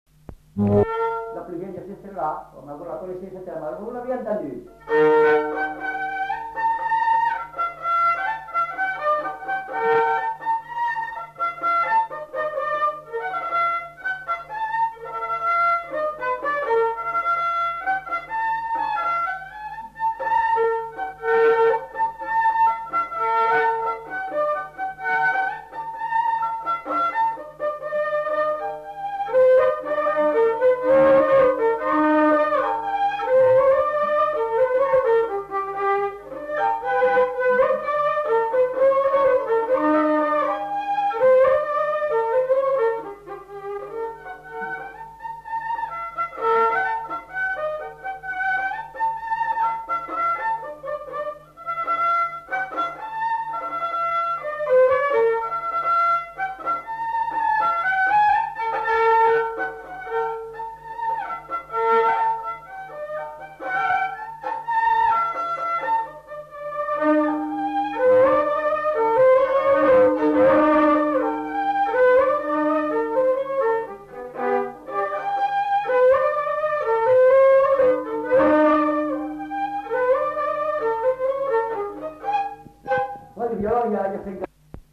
Lieu : Casteljaloux
Genre : morceau instrumental
Instrument de musique : violon
Danse : polka